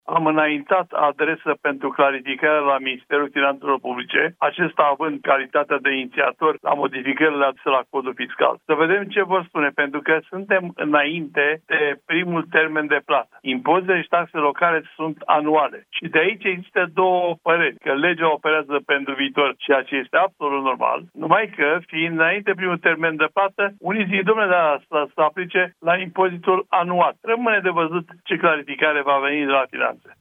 Edilii din țară așteaptă lămuriri de la autorități, spune la Europa FM Emil Drăghici, primarul comunei dâmbovițene Vulcana-Băi și preşedintele Asociaţiei Comunelor.